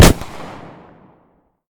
pistol-shot-06.ogg